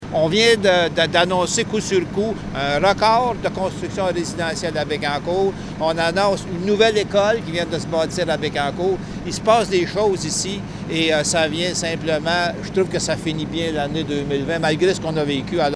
Une cérémonie de levée de la première pelletée de terre a eu lieu jeudi en présence des propriétaires des trois entreprises et du maire Jean-Guy Dubois, qui se réjouit qu’elles aient choisi Bécancour pour s’implanter :